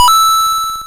M1_Coin.wav